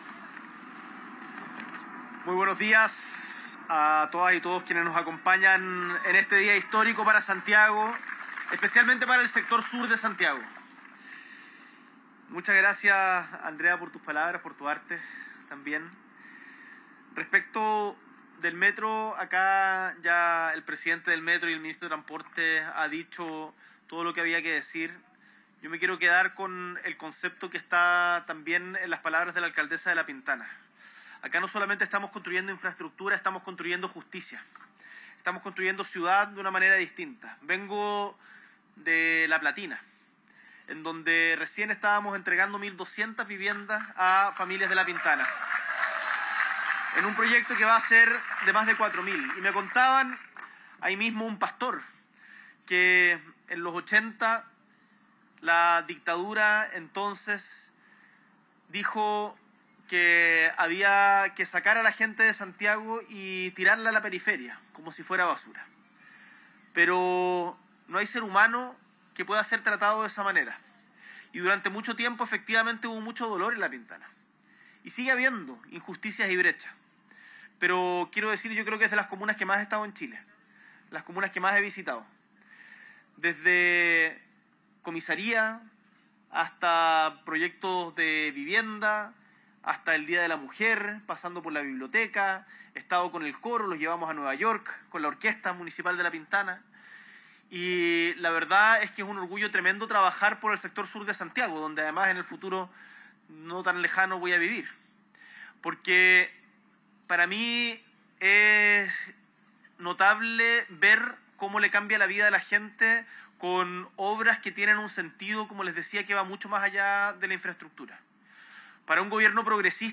Este martes 19 de agosto, en La Pintana, donde se emplazarán los futuros talleres y cocheras, el Presidente de la República, Gabriel Boric Font, dio inicio a las obras de la Línea 9 del Metro de Santiago, que permitirá ir desde Puente Alto al centro de Santiago en aproximadamente 35 minutos.